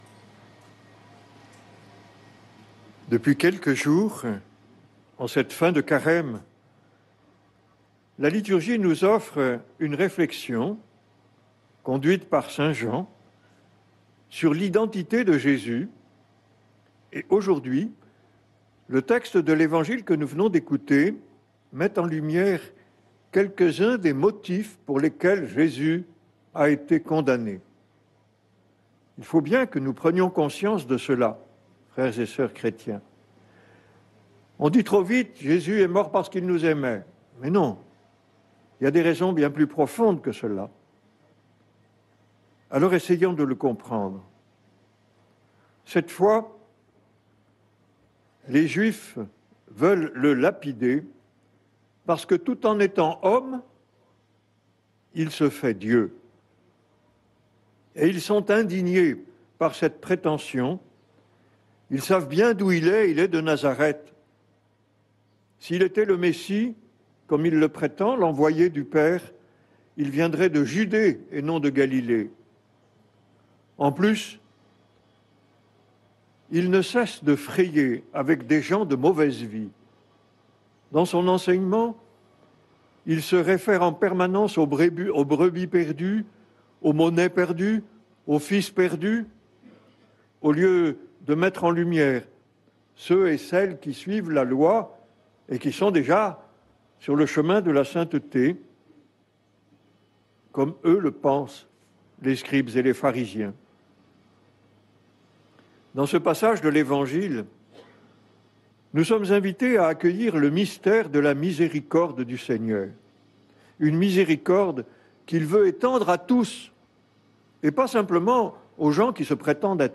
homeliemisericorde.mp3